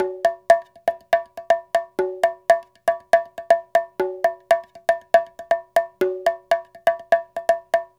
Bongo 11.wav